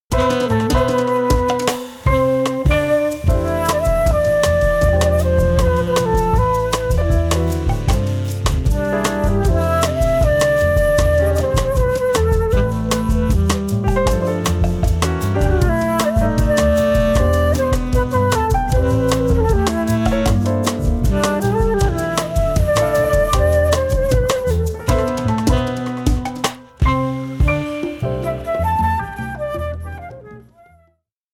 Hip modern Jazz